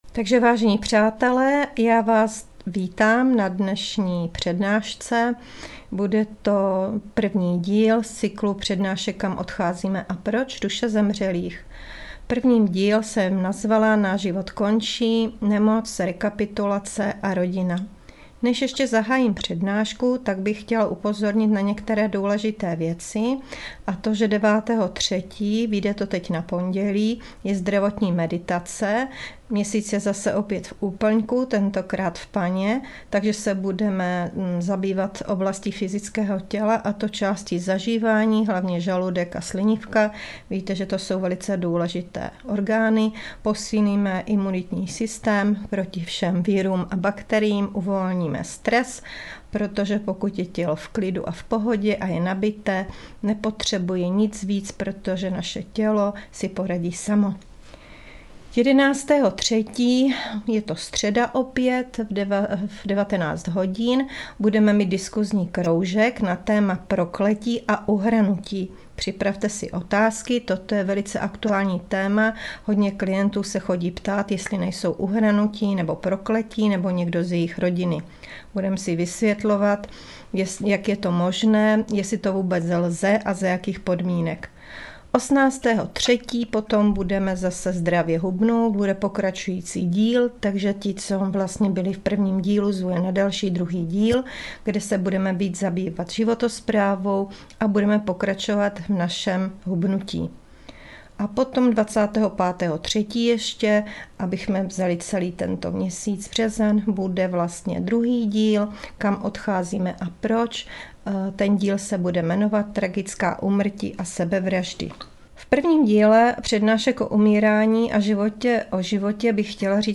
Přednáška Duše zemřelých, díl 1. - Kam odcházíme